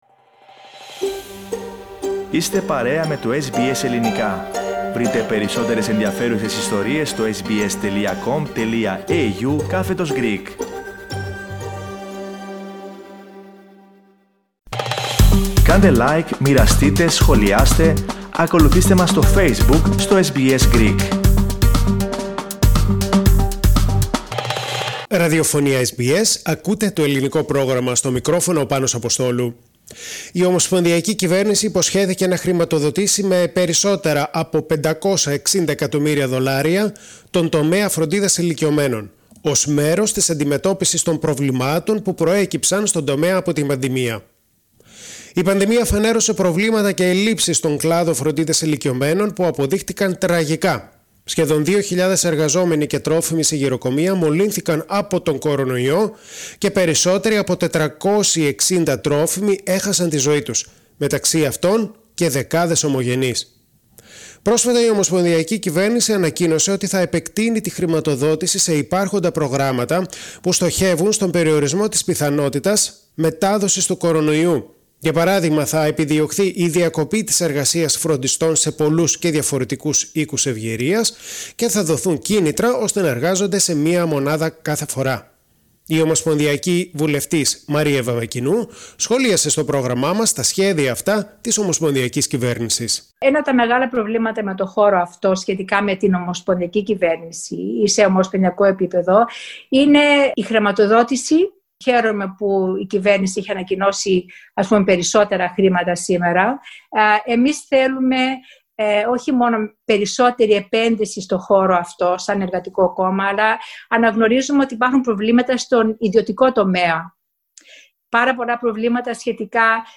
Η ομοσπονδιακή κυβέρνηση υποσχέθηκε να χρηματοδοτήσει με περισσότερα από 560 εκατομμύρια δολάρια τον τομέα φροντίδας ηλικιωμένων ως μέρος της αντιμετώπισης των προβλημάτων που προέκυψαν στον τομέα από την πανδημία. Η ομοσπονδιακή βουλευτής Μαρία Βαμβακινού μιλά στο SBS Greek.